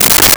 Plastic Lid
Plastic Lid.wav